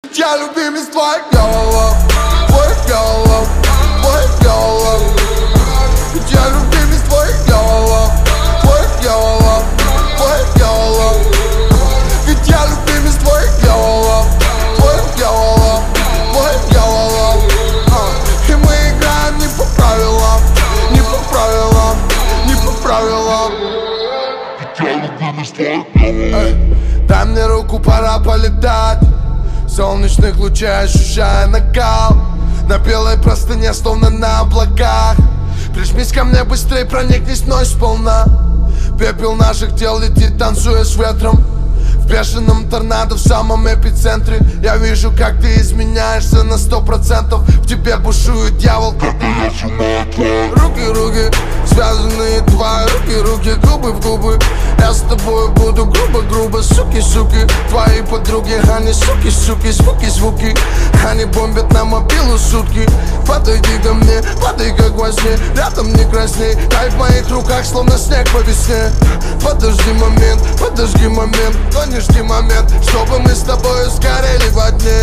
рэп
Хип-хоп
красивый мужской вокал